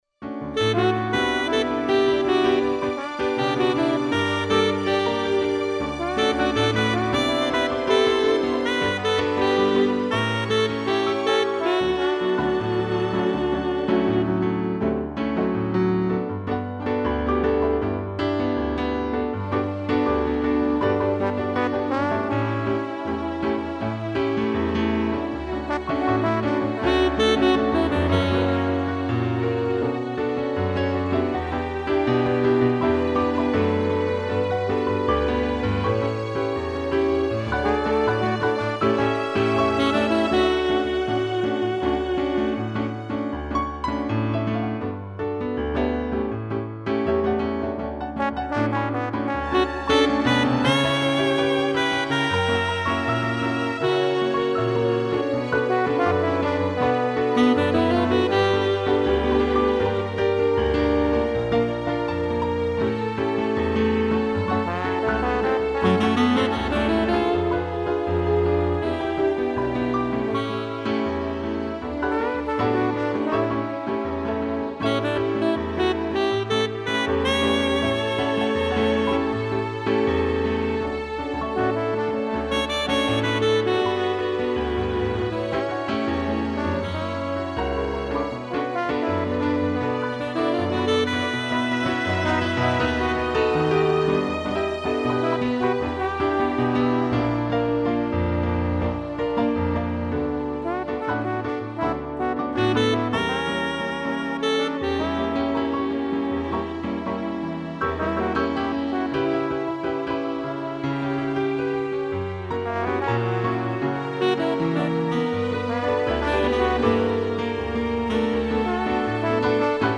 2 pianos, sax, trombone e strings